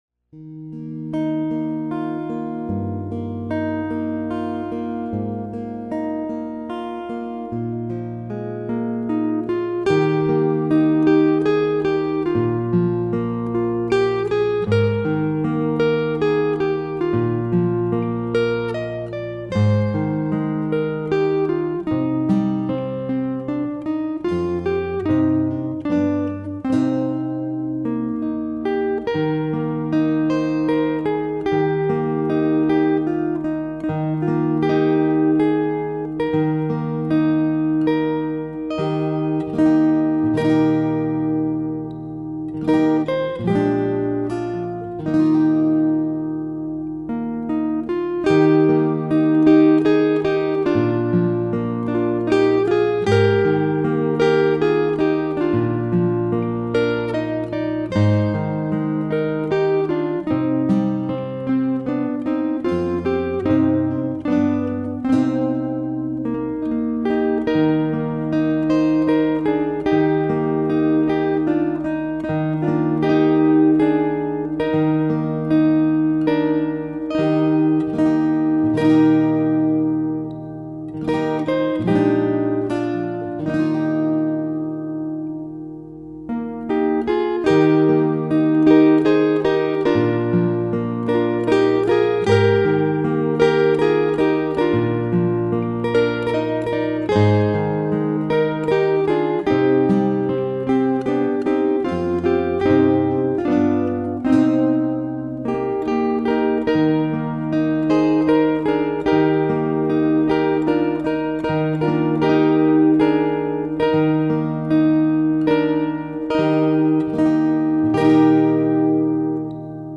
기묘하신 나의 구주(기타연주)